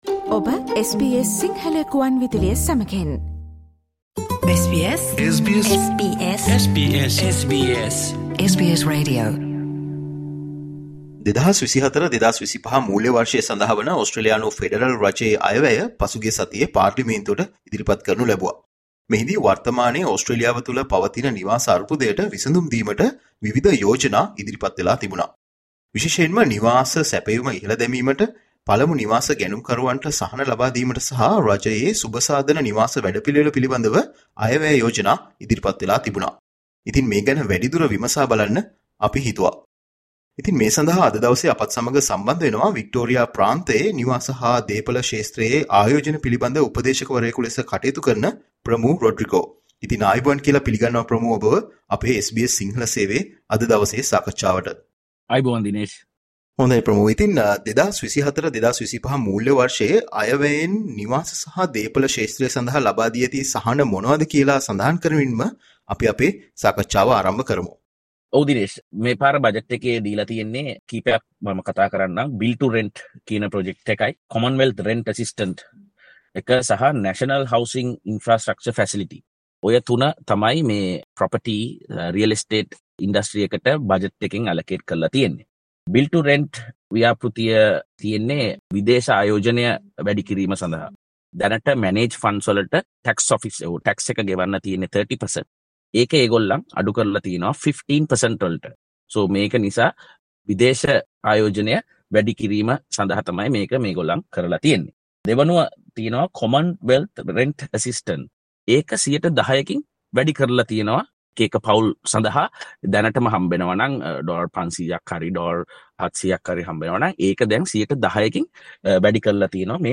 There is a lot of pressure on the government to address Australia's severe housing crisis. There were several important decisions made by the government in this budget. Listen to SBS Sinhala interview for more information.